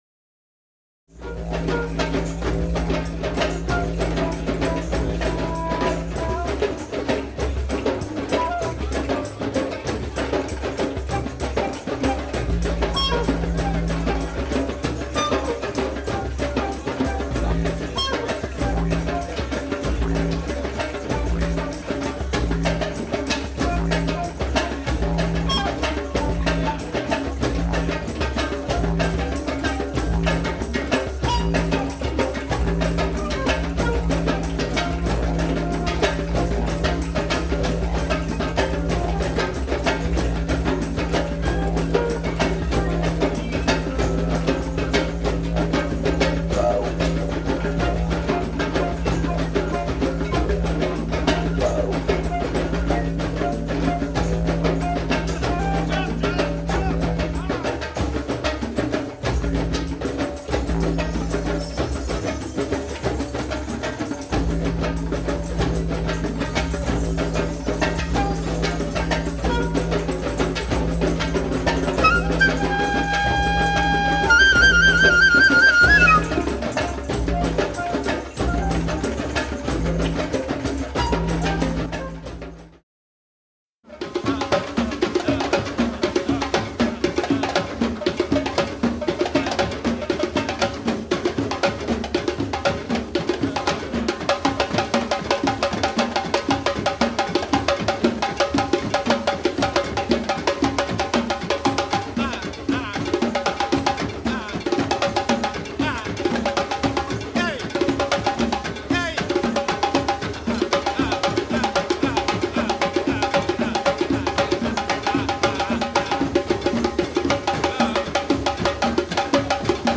You can hear and feel how much fun, and excitement
None of it was planned out.
and it all sounds pretty amazing.